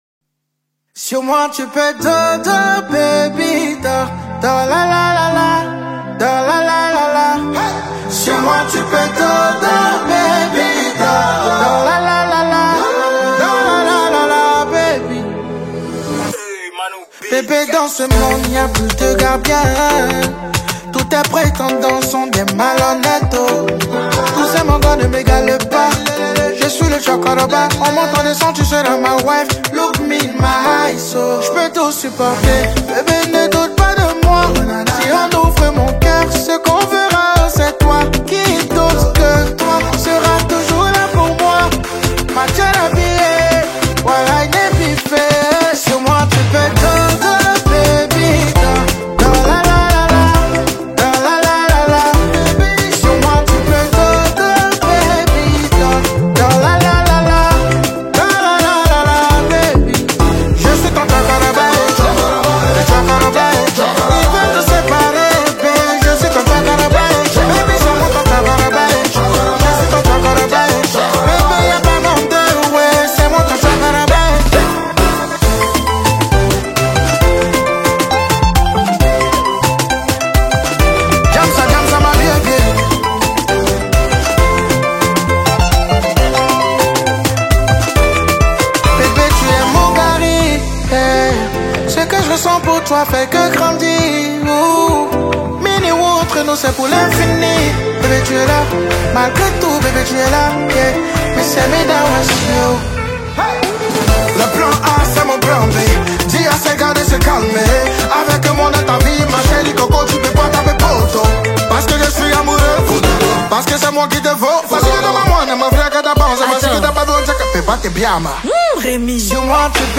| Afro décalé